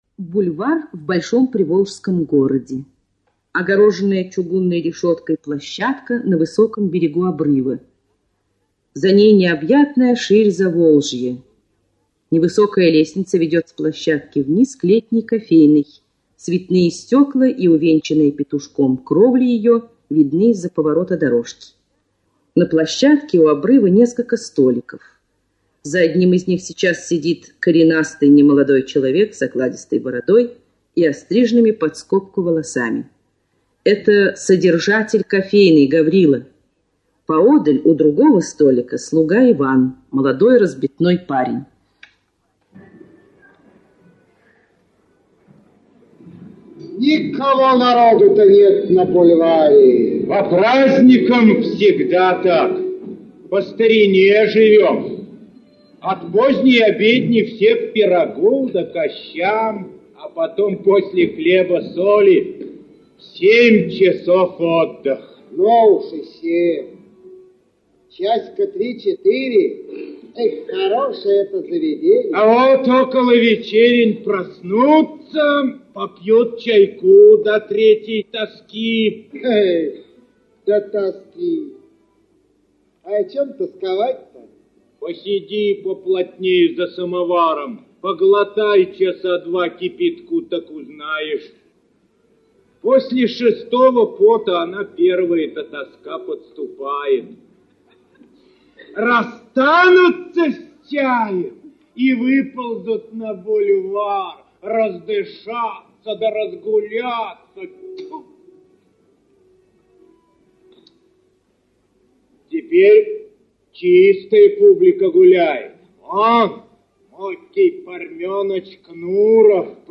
Аудиокнига Бесприданница (спектакль) | Библиотека аудиокниг